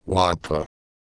Worms speechbanks
Whatthe.wav